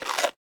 eat2.ogg